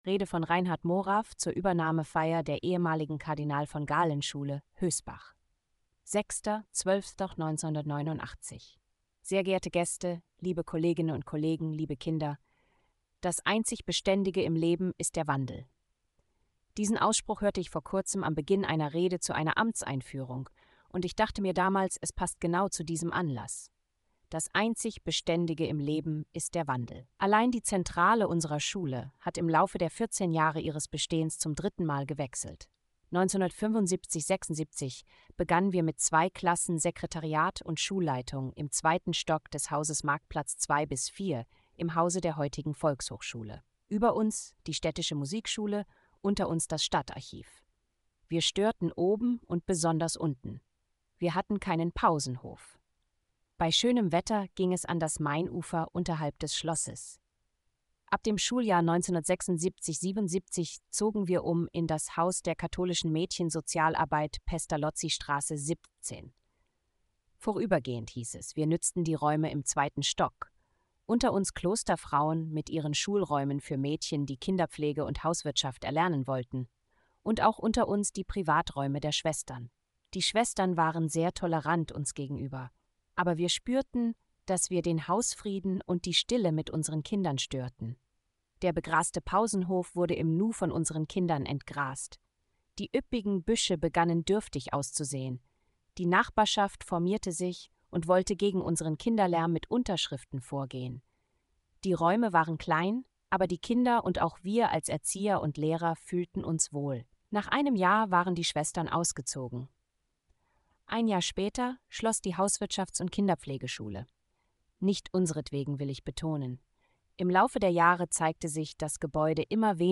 Rede zur Übernahmefeier der Schule in Hösbach